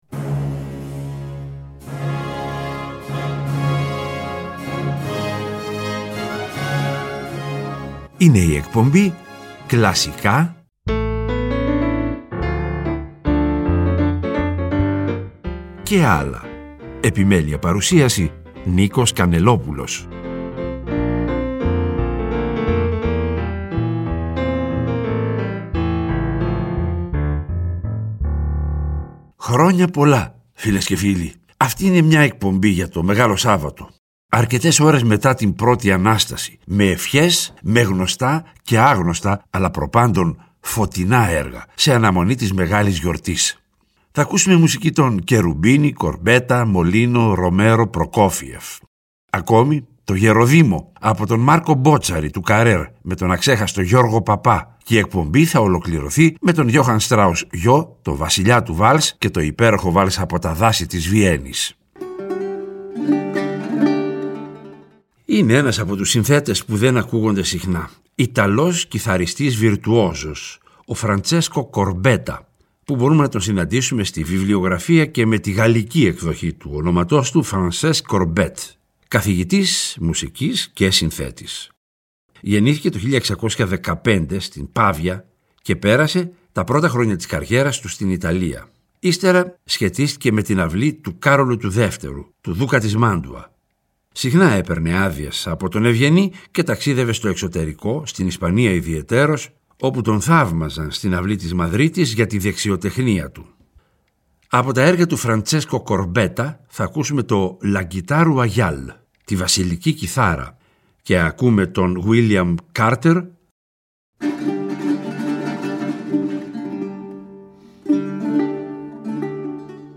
Η εκπομπή ολοκληρώνεται με 𝗝𝗼𝗵𝗮𝗻𝗻 𝗦𝘁𝗿𝗮𝘂𝘀𝘀 𝗜𝗜 και το βαλς «Από τα δάση της Βιέννης».
Κλασικη Μουσικη